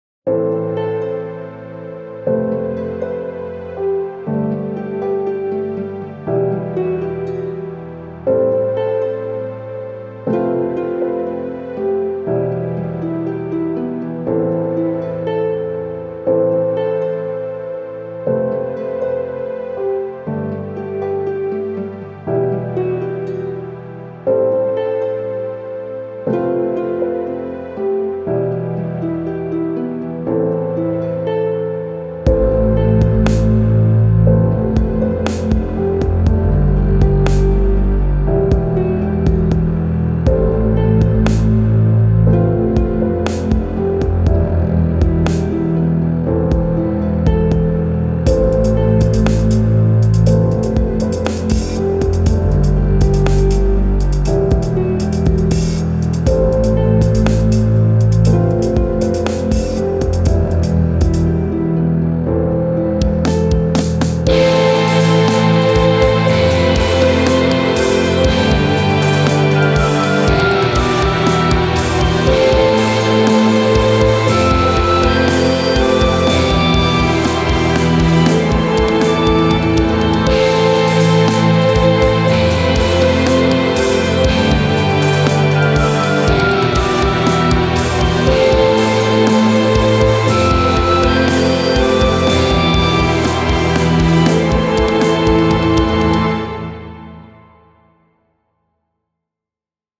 感動
シリアス
壮大
悲しい